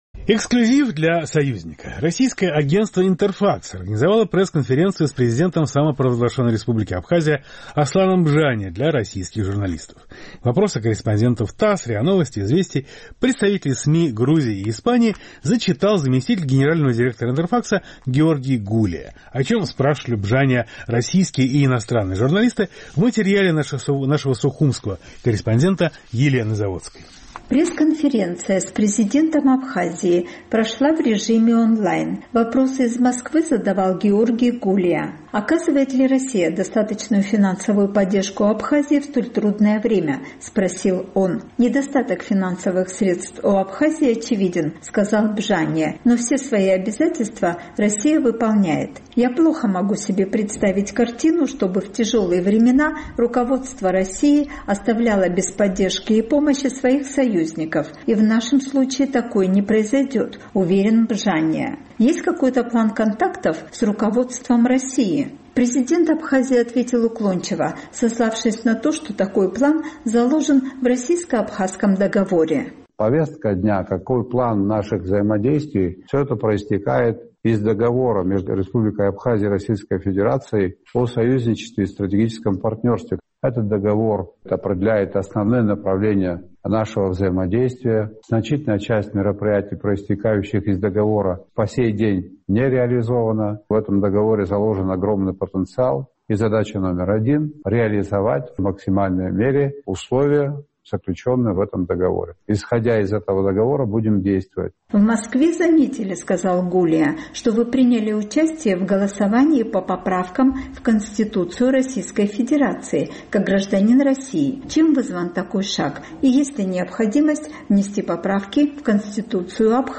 Российское агентство «Интерфакс» организовало пресс-конференцию с президентом Абхазии Асланом Бжания для иностранных журналистов.